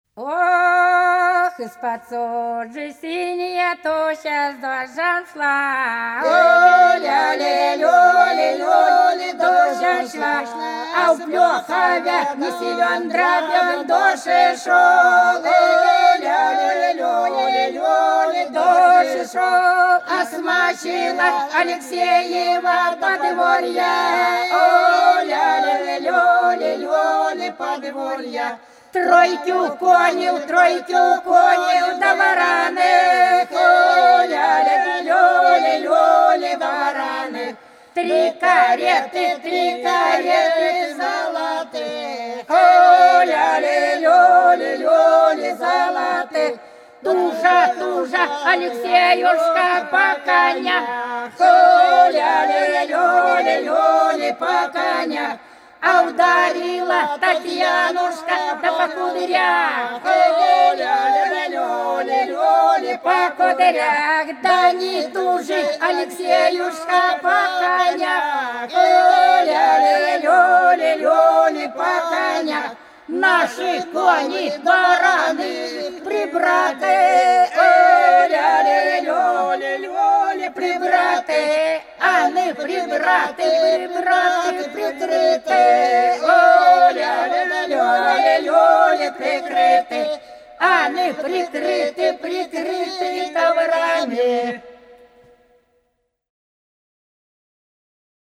По-над садом, садом дорожка лежала Ох, из-под тучи синяя туча с дождём шла - свадебная (с.Плёхово, Курская область)
13_Ох,_из-под_тучи_синяя_туча_с_дождём_шла_(свадебная).mp3